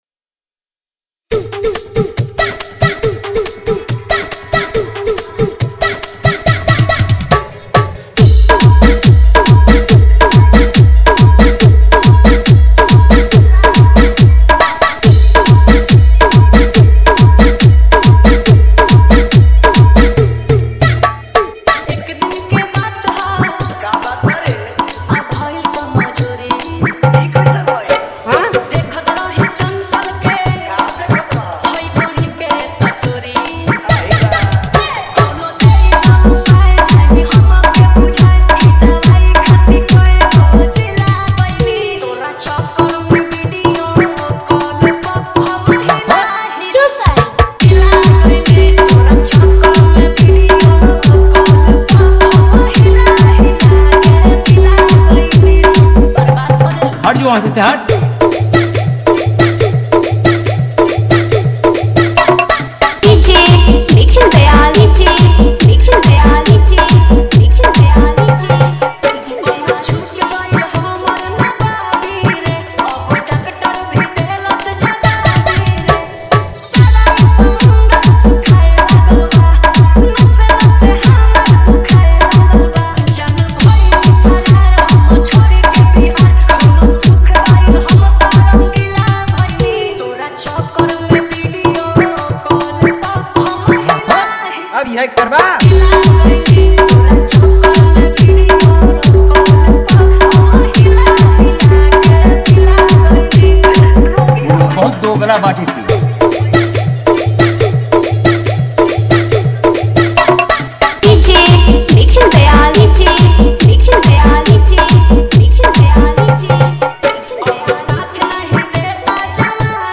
Bheem Jayanti Dj Remix Songs